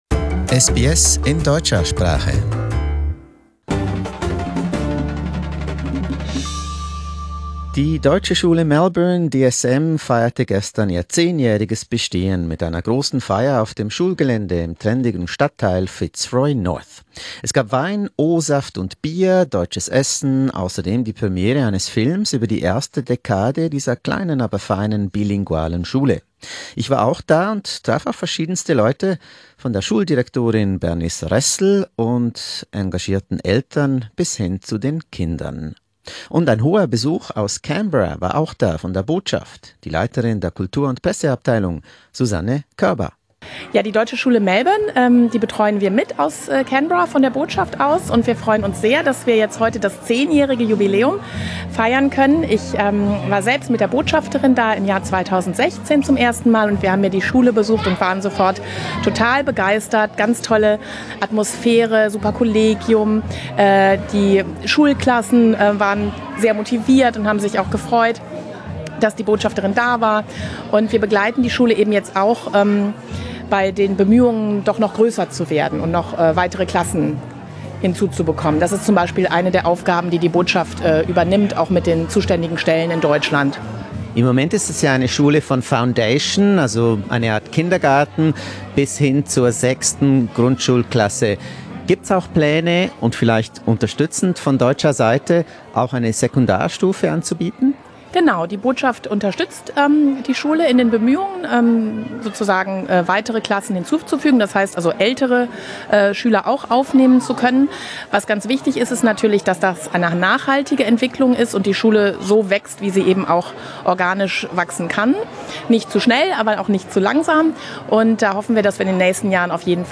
On Sunday 20 May, the German School Melbourne (DSM) celebrated its 10th anniversary.
SBS German was there to speak to staff, guests, parents and kids.